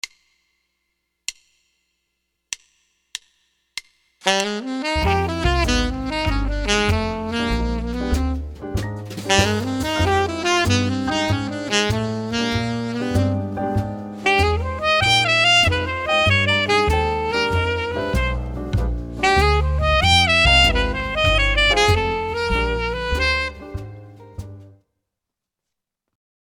I tried offsetting the rhythm by six 1/8th notes and came up with this: